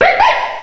cry_not_zorua.aif